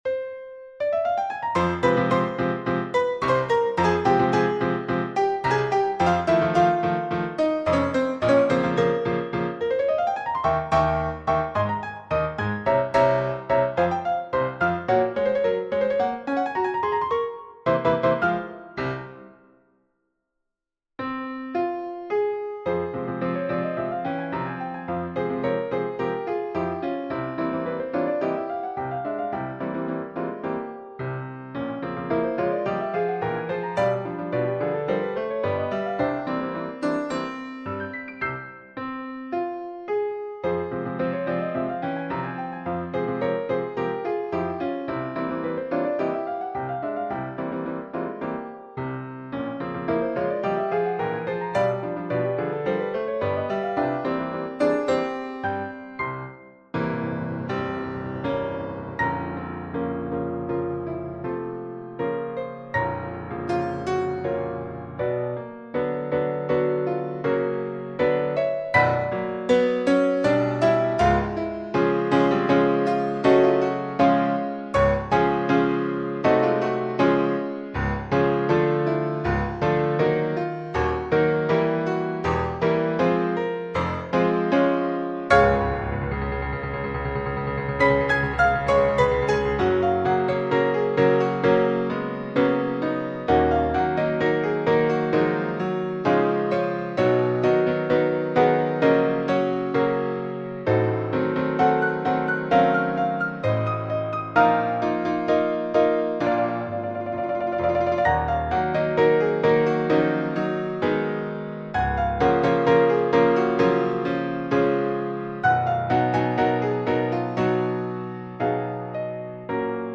SAB mixed choir and piano
世俗音樂